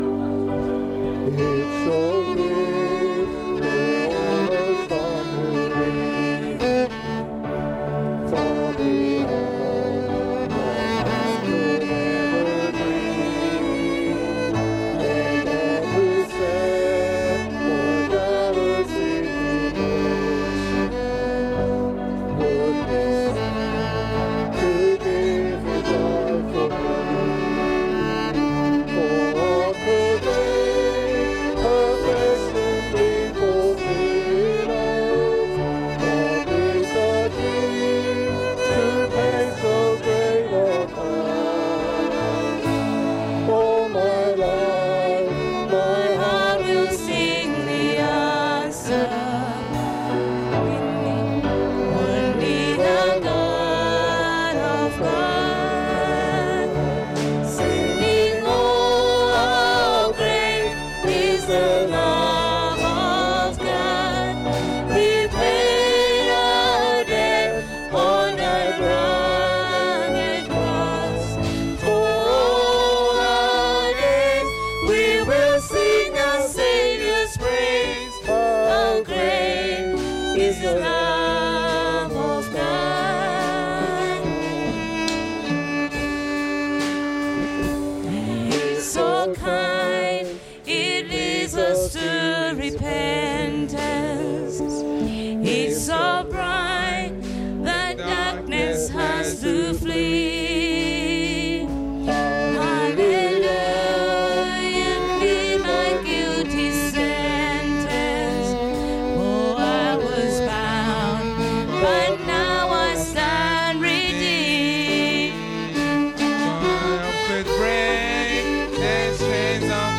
Communion service - Sittingbourne Baptist Church